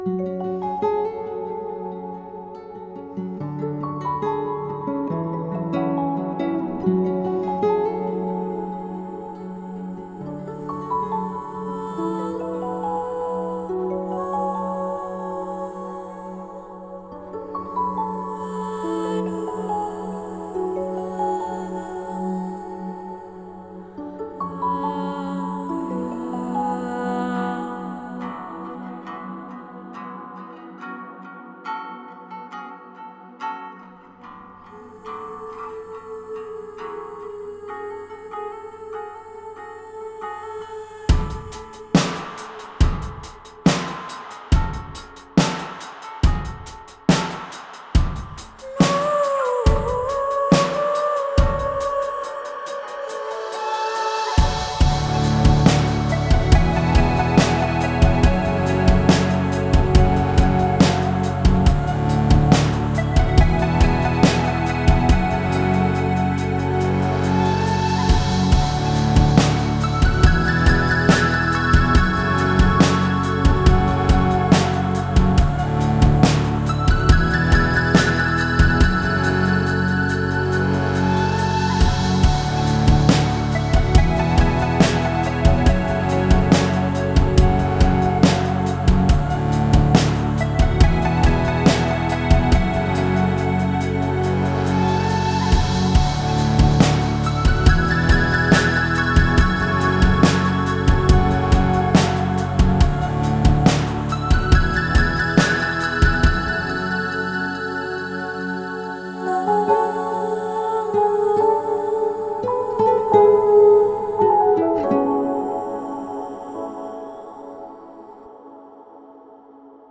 это трек в жанре фолк-рок